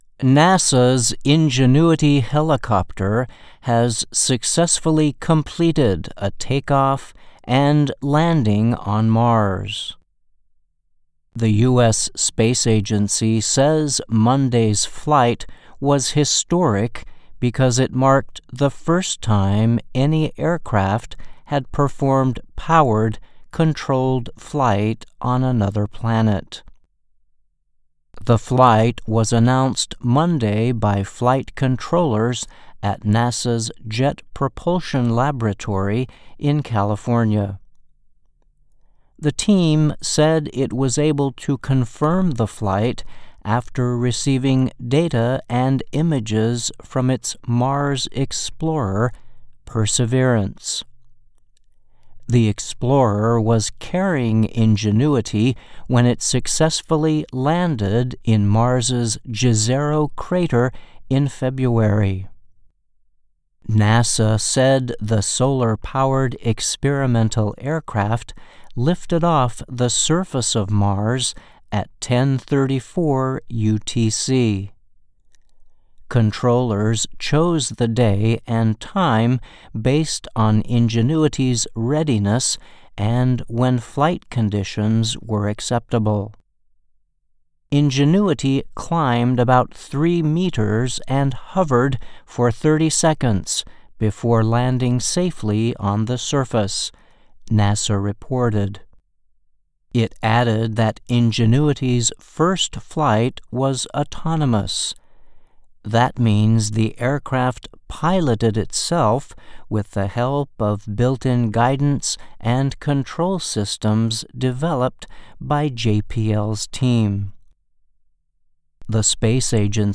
慢速英语:NASA火星直升机Ingenuity成功实现历史性首飞